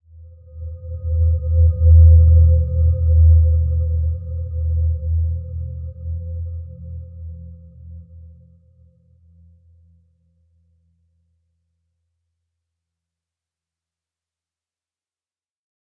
Dreamy-Fifths-E2-f.wav